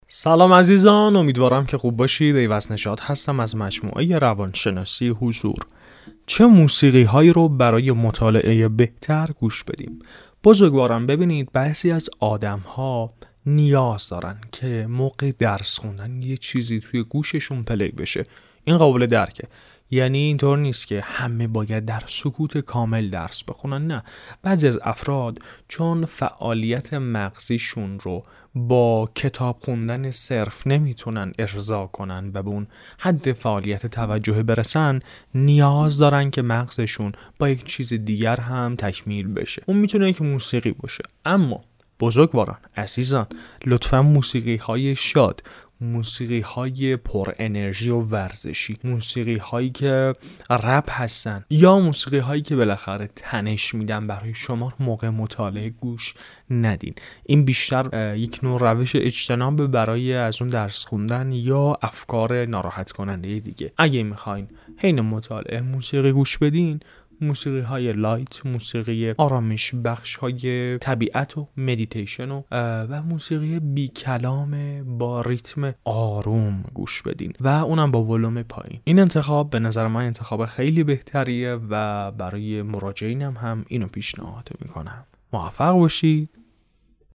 موسیقی-برای-مطالعه-بهتر.mp3